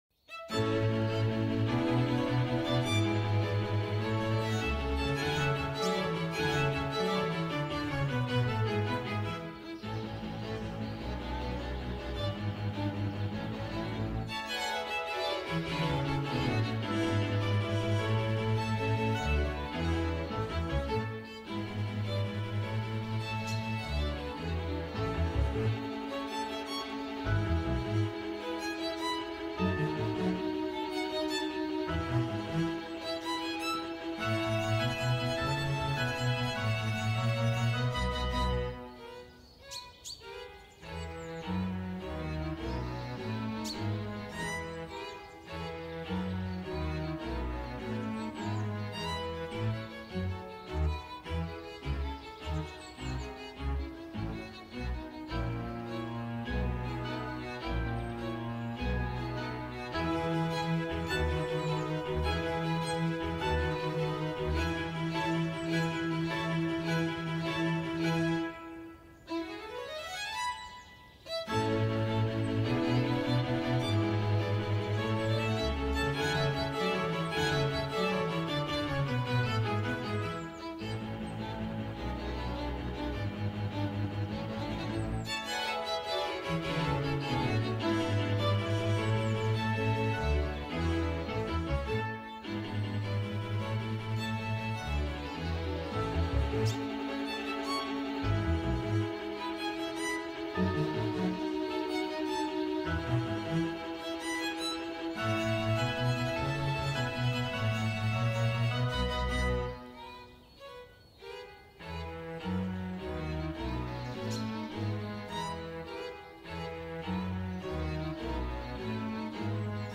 Mozart – Violín para enfoque y sesiones de lectura